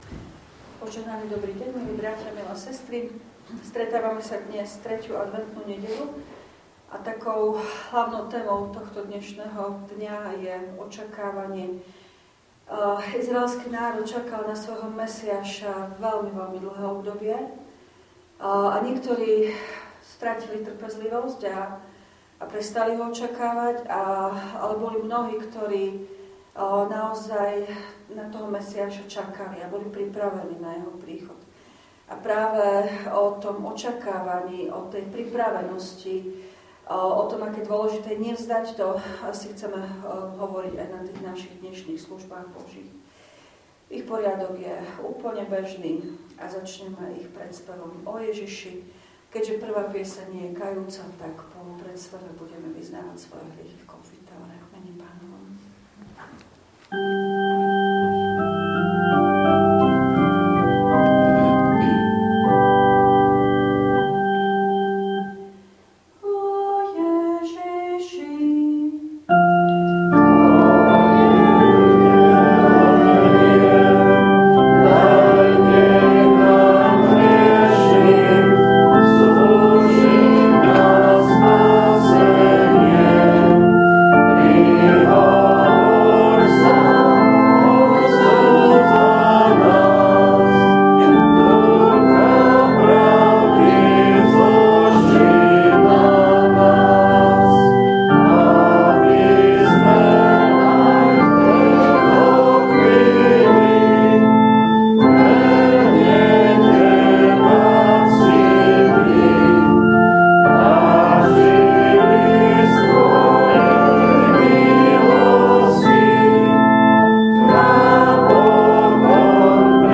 V nasledovnom článku si môžete vypočuť zvukový záznam zo služieb Božích – 3. adventná nedeľa.